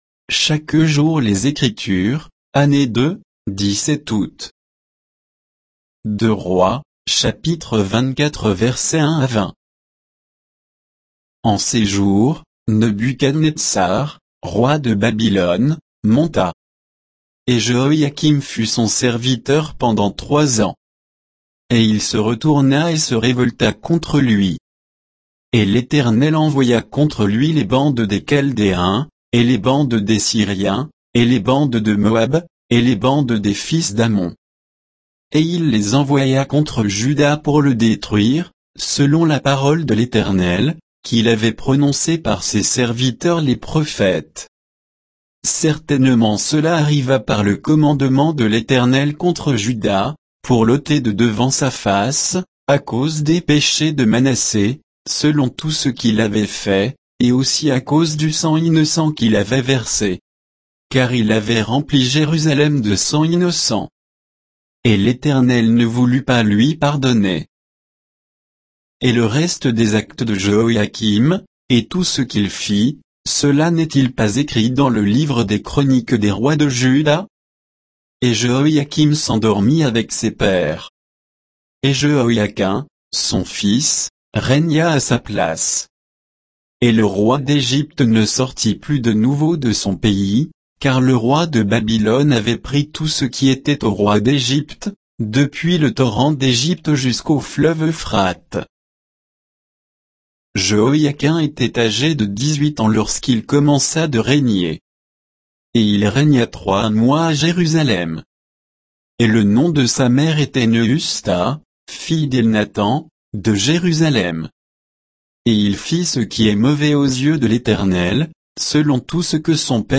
Méditation quoditienne de Chaque jour les Écritures sur 2 Rois 24, 1 à 20